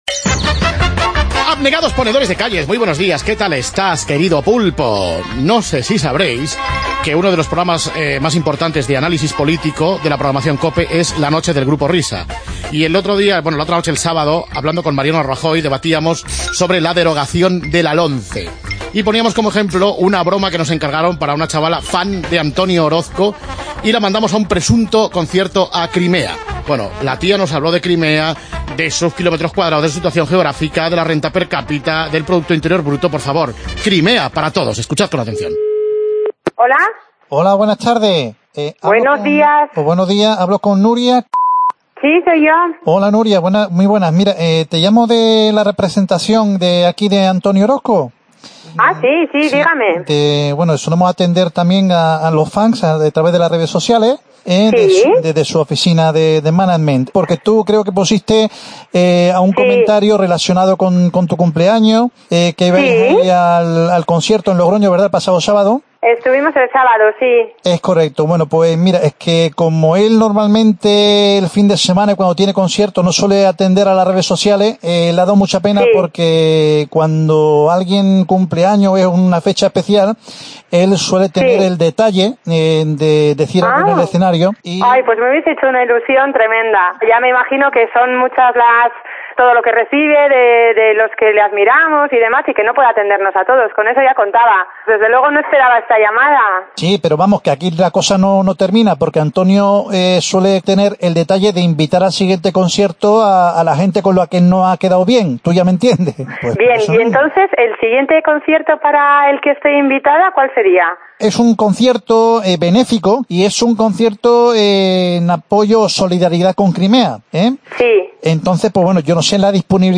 El toque de humor